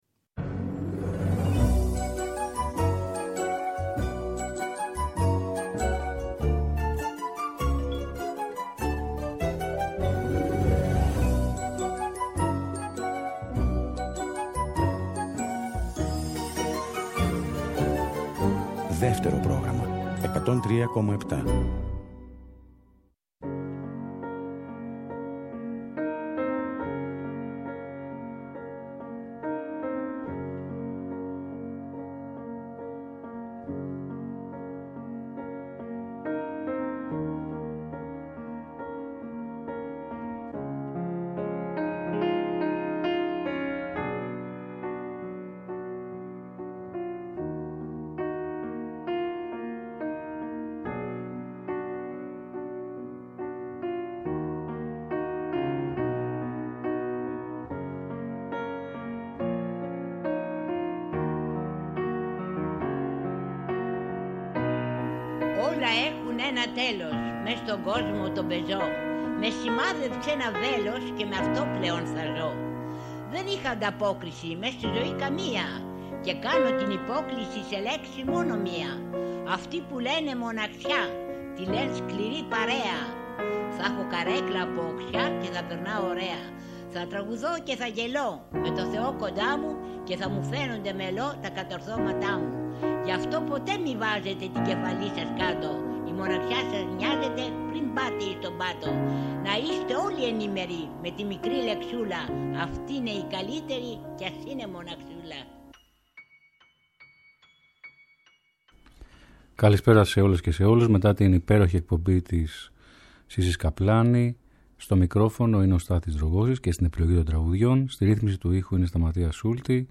Θα ακουστούν καινούρια και παλιά τραγούδια